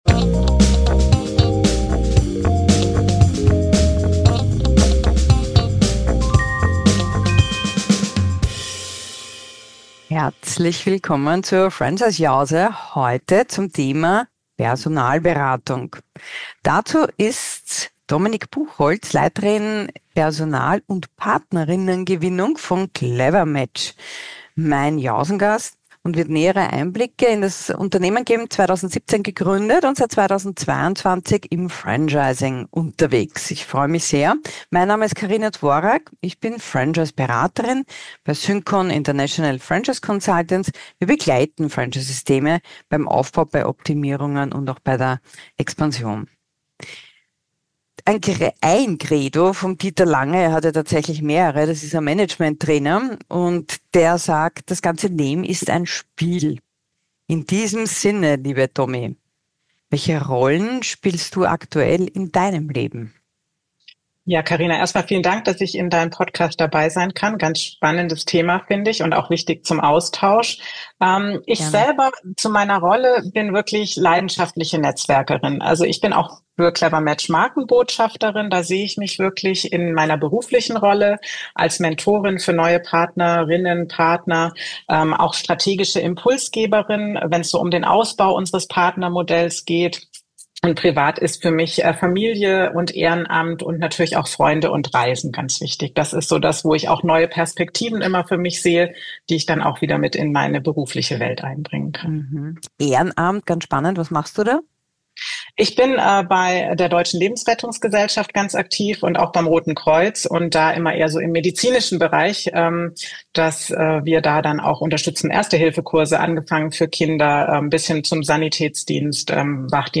Ein Gespräch über Partnermodell, Impulsgeberin, Set-up, Recruiting-Software und Onboarding